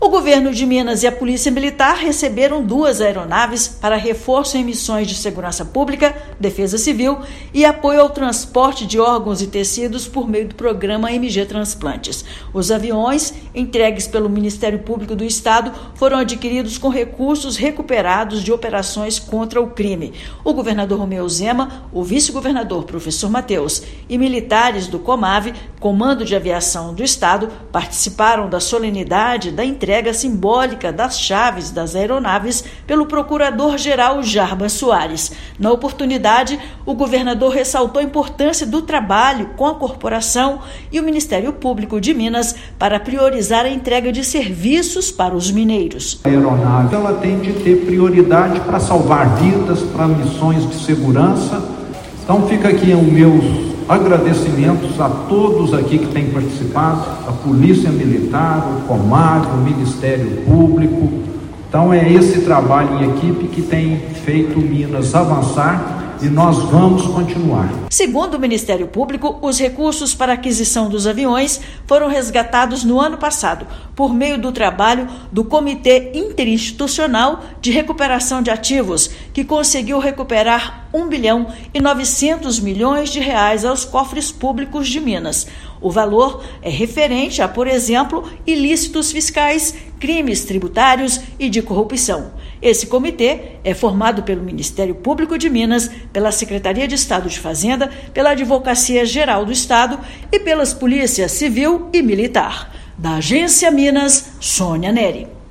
[RÁDIO] Governo de Minas recebe aeronaves que vão reforçar transporte de órgãos vitais e a segurança pública em todo o estado
Aviões entregues pelo Ministério Público de Minas Gerais foram adquiridos com recursos resgatados em operações contra ilícitos fiscais, crimes tributários e corrupção. Ouça matéria de rádio.